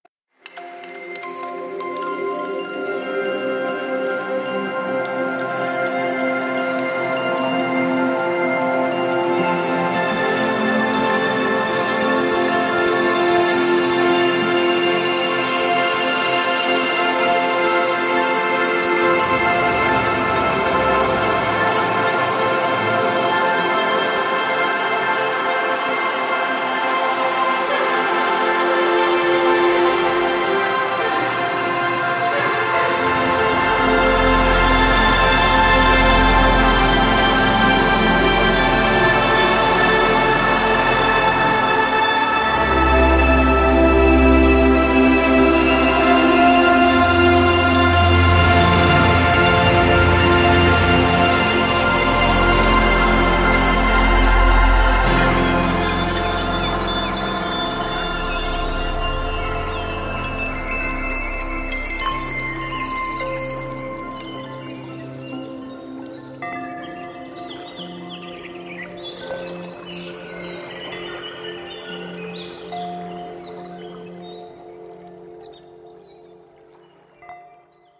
3. 氛围铺底
Zero-G Celestial Visions是一款多层次的声音纹理Kontakt乐器，可以用来创造迷人的音景。
你可以利用复杂的调制控制，空灵的效果，和动态的分层来打造独一无二的演变音景。